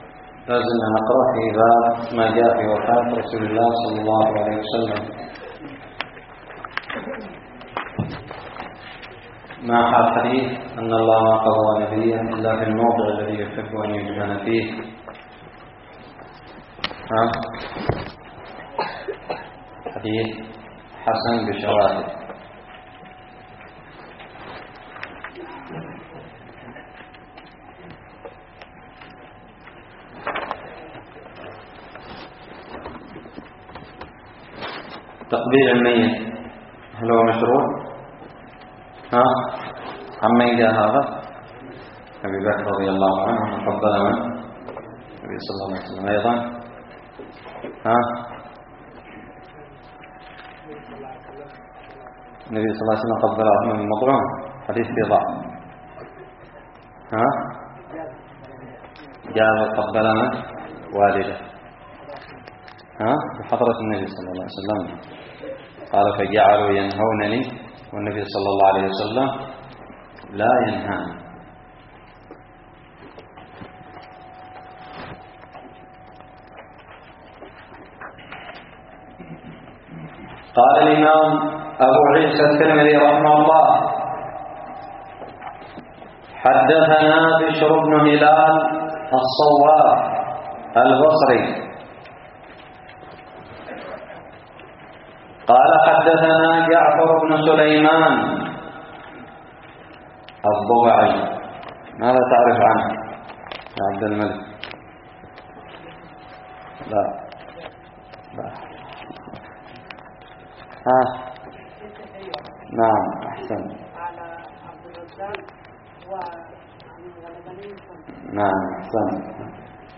الدرس التاسع والعشرون بعد المائة من شرح كتاب الشمائل المحمدية
ألقيت بدار الحديث السلفية للعلوم الشرعية بالضالع